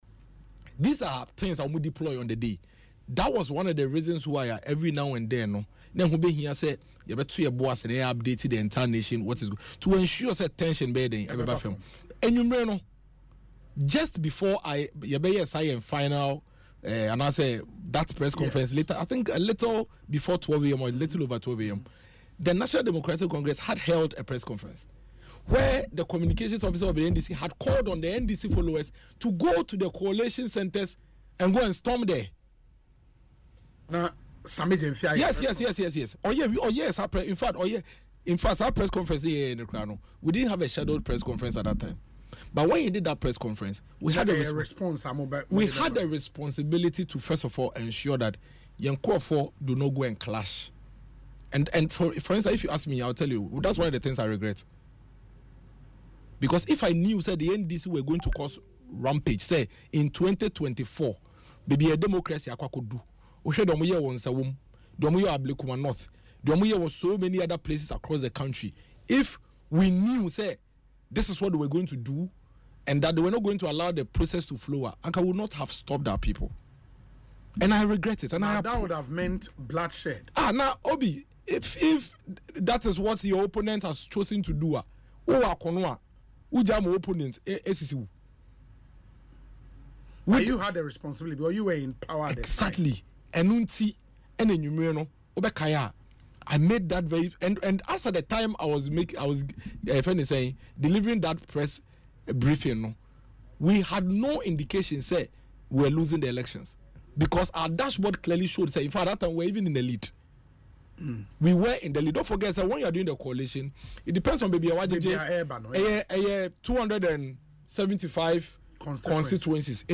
Speaking in an interview on Asempa FM’s Ekosii Sen